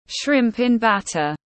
Bánh tôm tiếng anh gọi là shrimp in batter, phiên âm tiếng anh đọc là /ʃrɪmp ɪn ˈbæt.ər/
Shrimp in batter /ʃrɪmp ɪn ˈbæt.ər/